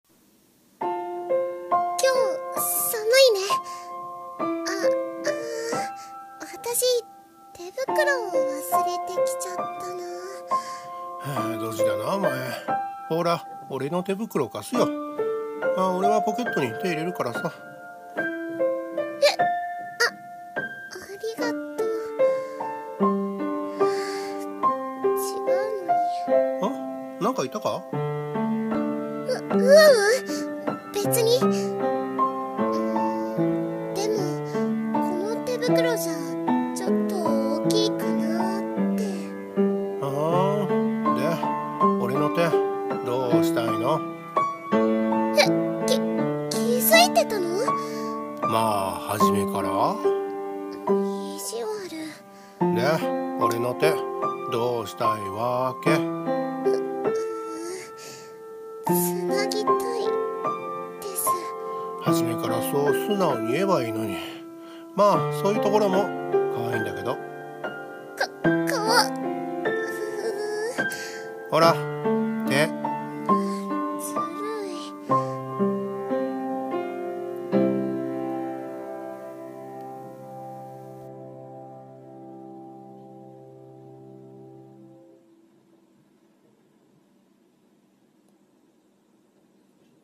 【声劇】手を繋ぎたくて【掛け合い】